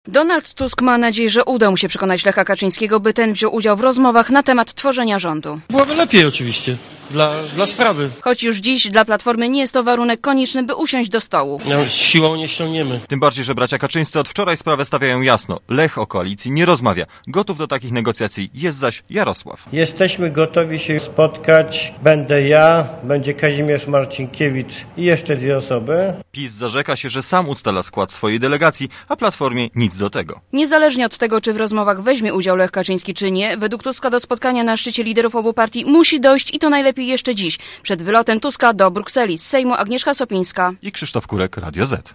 Relacja reporterów Radia ZET